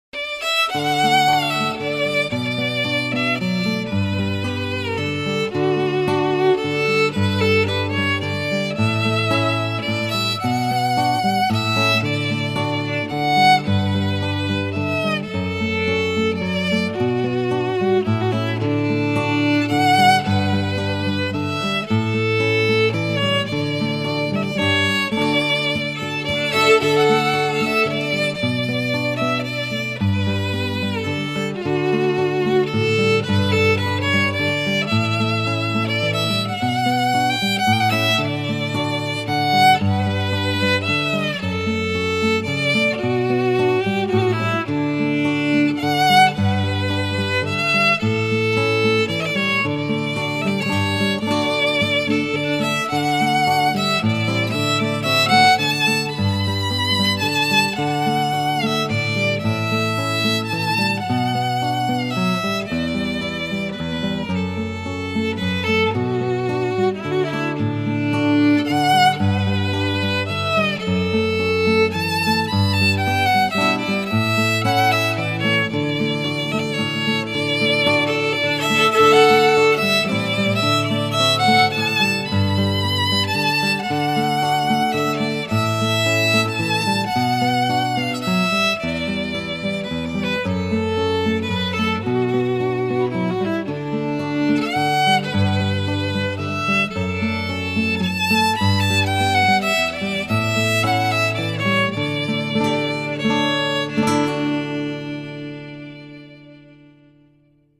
This Lansing Irish Band brings together guitars, mandolin, fiddle, dobro and harmony vocals all in one package, performing swinging roots music ranging in styles from country/bluegrass, swing/blues, celtic/world music with exuberant virtuosity.
Fiddle, guitars and vocals.